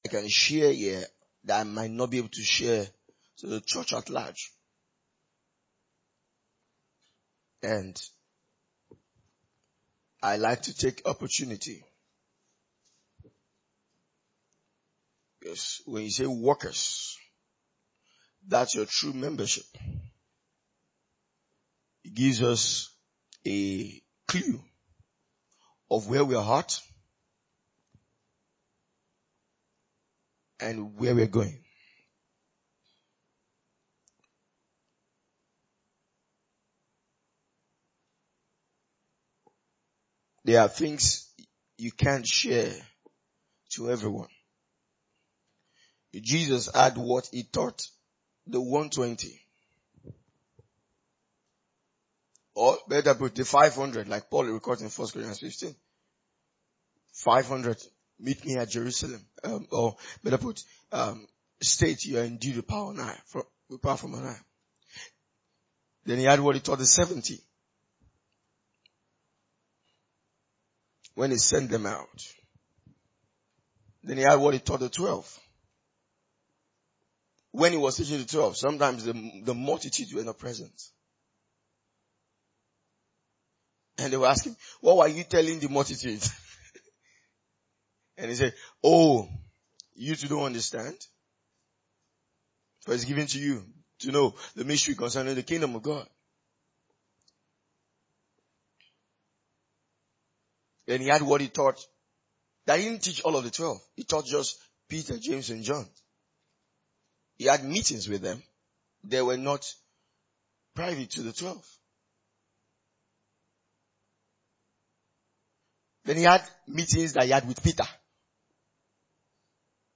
A teaching from our annual workers’ meeting. We explore the church’s role in uplifting their pastors.